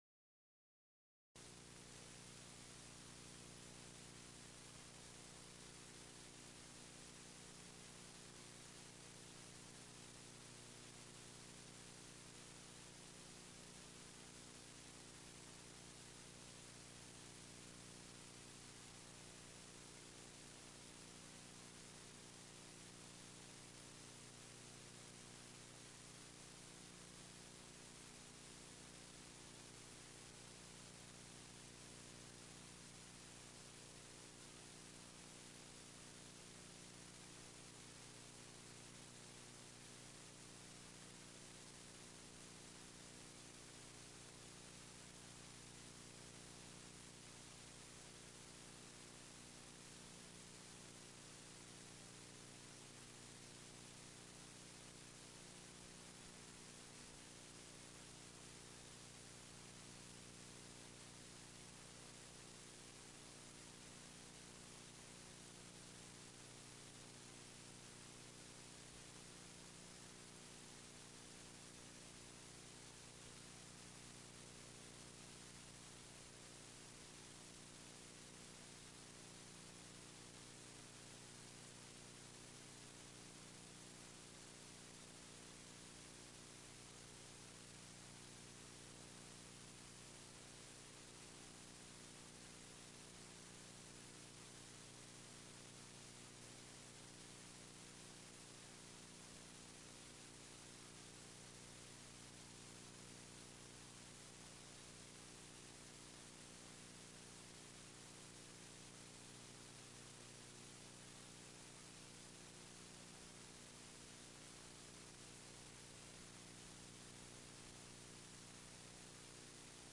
Rom: K102